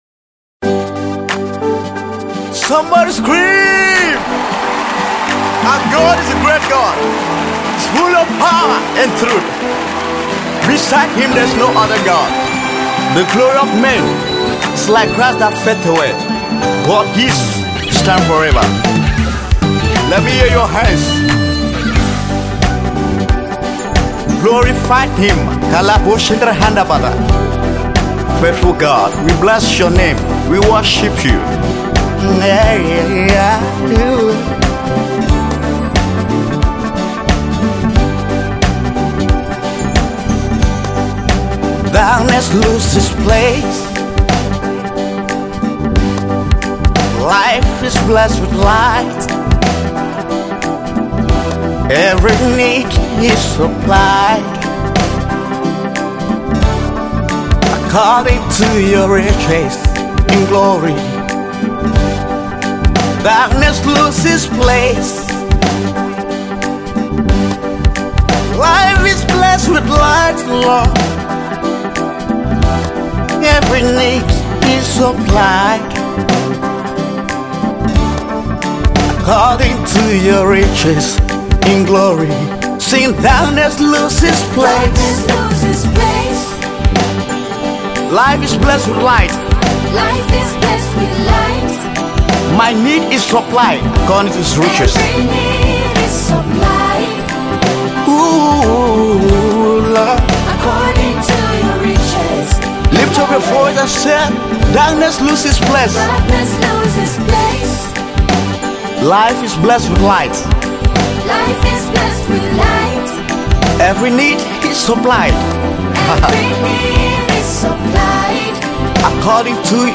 contemporary gospel praise and worship piece
This is a sound of healing and victory in Christ Jesus.